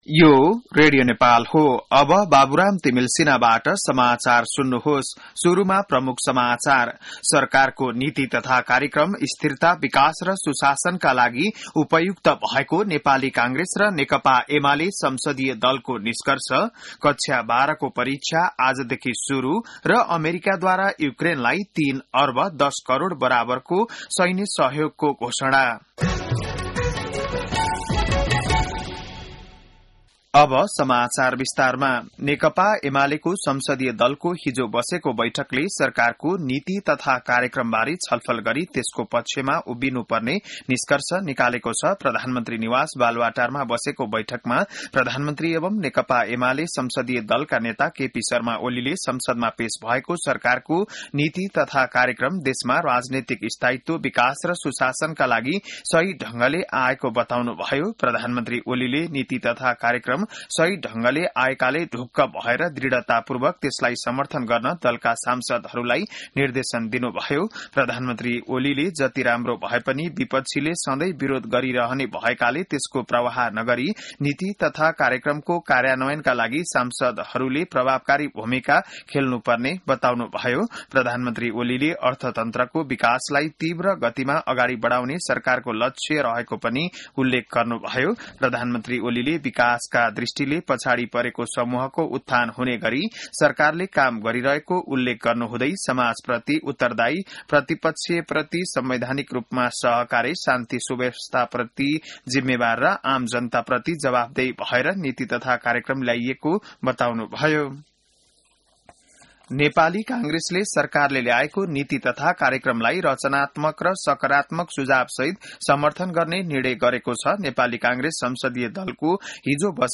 बिहान ९ बजेको नेपाली समाचार : २१ वैशाख , २०८२